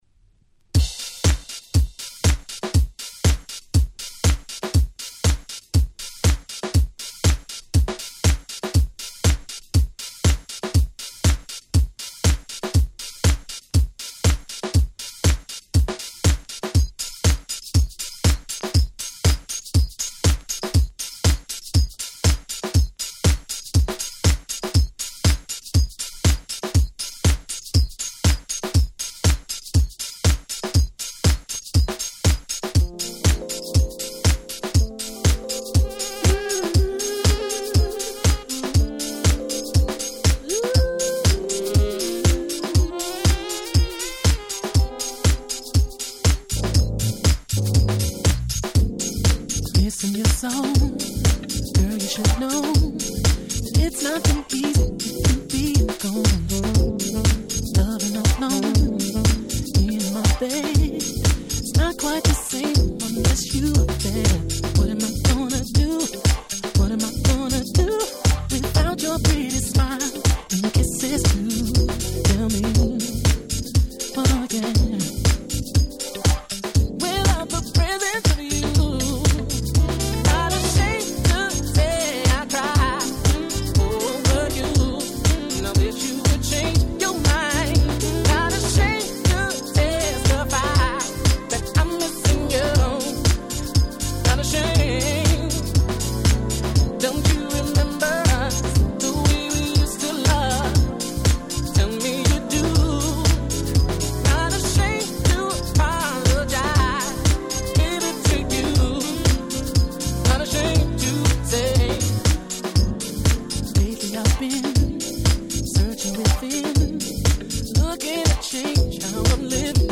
03' Very Nice Neo Soul/Jazzy R&B ♪
この方、詳細は不明ですがUSのSax奏者の方。